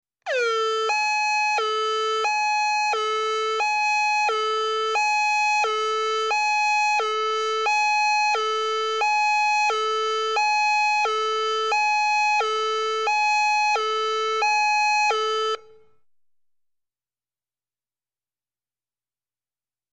High / Low Siren; Close Perspective 2.